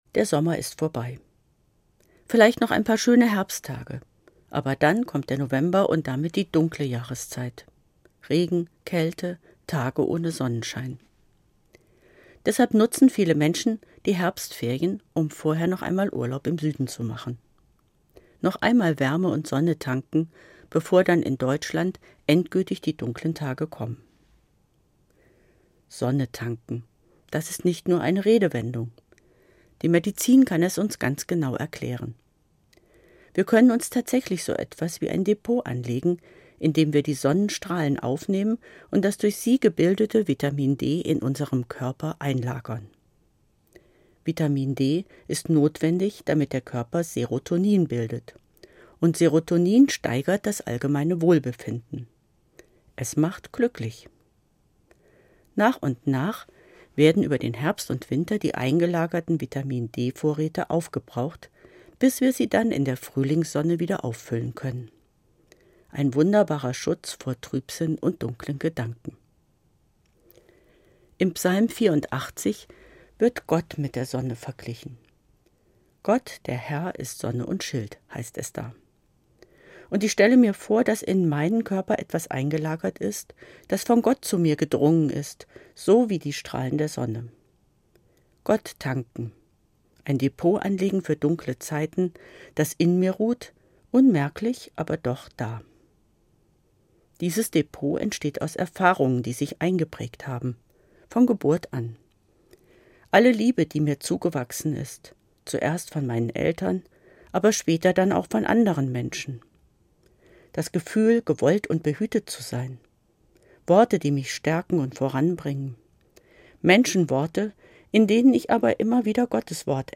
Evangelische Pfarrerin, Kassel